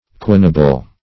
Search Result for " quinible" : The Collaborative International Dictionary of English v.0.48: Quinible \Quin"i*ble\, n. [L. quini five each.]